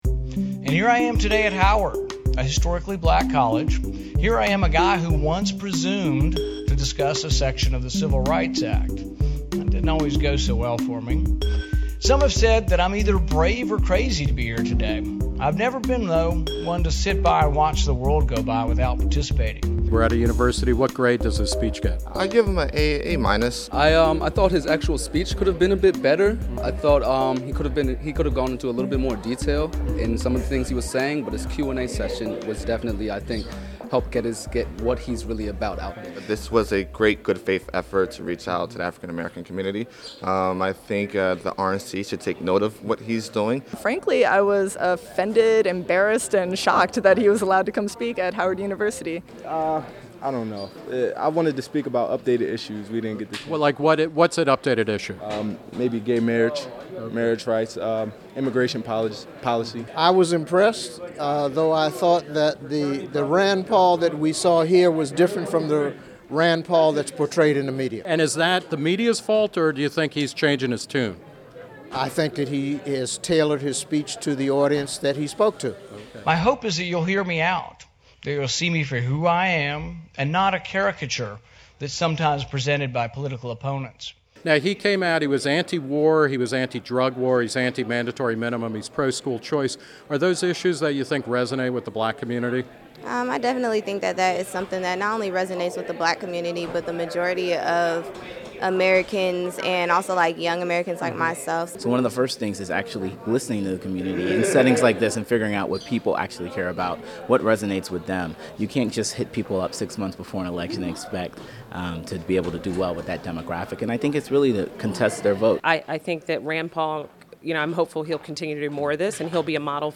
Sen. Rand Paul (R-Ky.) spoke to an audience at the historically black college Howard University yesterday. The junior senator from the Bluegrass State said he wanted to start a conversation between African-American voters and Republicans, who pulled just 7 percent of the black vote in the 2012 presidential race.